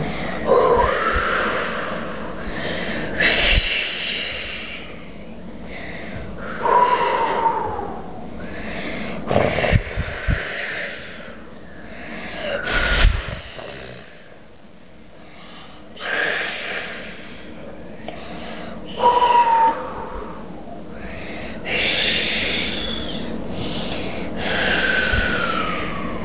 › Wind [WAV | 565 KB] und Donner als einmalige Reaktion auf den gelben Blitz, mit dem ein Kind auf die Bühne läuft.
SLH_Wind.wav